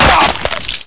zombi_trapped.wav